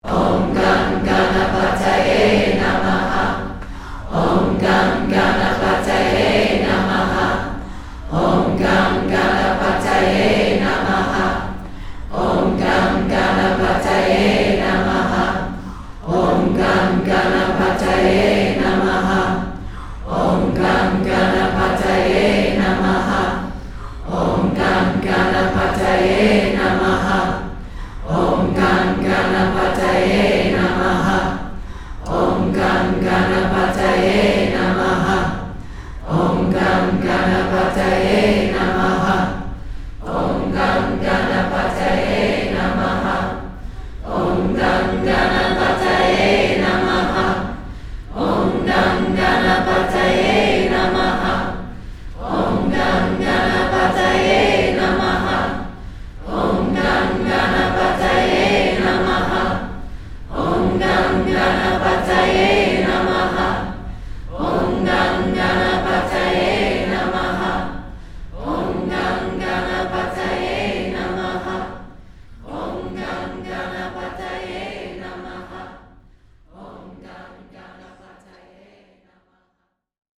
Please join us for these 17+ hours of nourishing, uplifting, often hilarious, peaceful, and powerful teachings from this miraculous 5-day Soul Convergence.